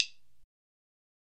鼓棒16位Zildjian单声道" 单回响鼓棒
描述：从摇滚乐队附带的Zildjian鼓棒上发出的单一处理过的敲击声。 通过Digitech RP 100进行录音。 使用了混响。
标签： 公共 鼓棒 处理